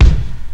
• 2000s Verby Bass Drum A Key 185.wav
Royality free kickdrum sample tuned to the A note. Loudest frequency: 246Hz